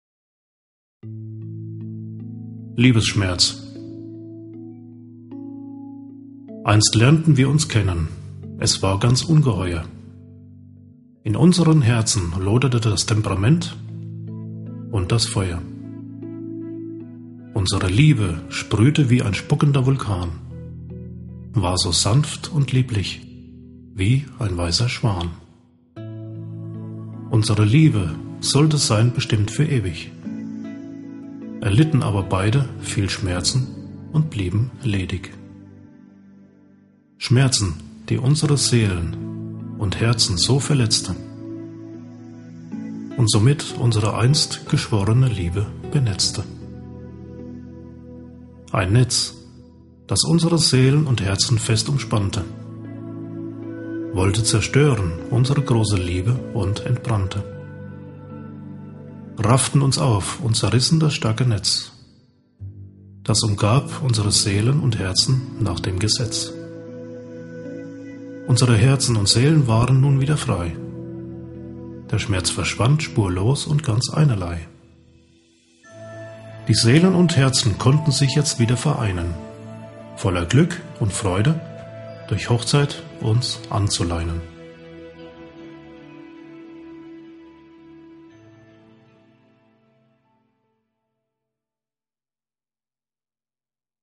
Der Klang seiner Stimme wirkt beruhigend und führt die Zuhörer in eine andere Welt voller Träume, Sehnsüchte und Verlangen nach Liebe.